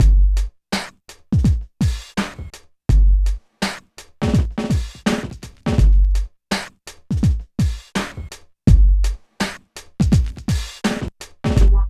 44 DRUM LP-L.wav